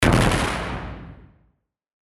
闇ダメージ
/ F｜演出・アニメ・心理 / F-30 ｜Magic 魔法・特殊効果
バコーン